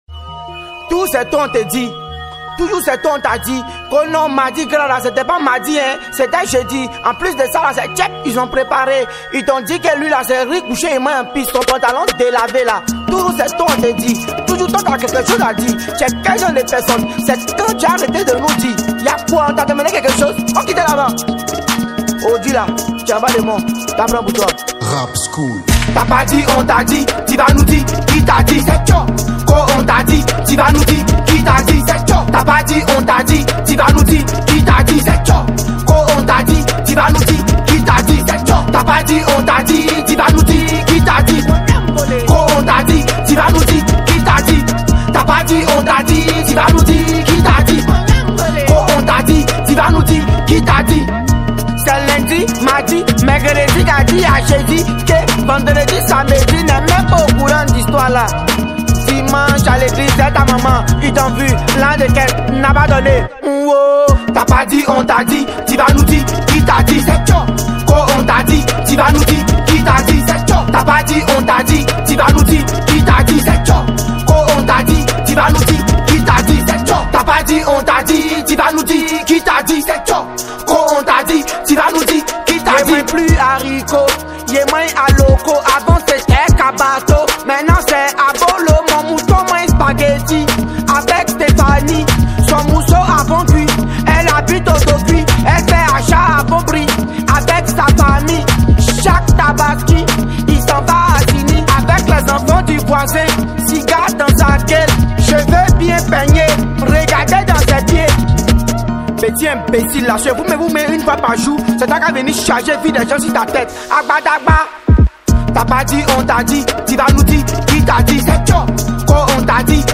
| Rap ivoire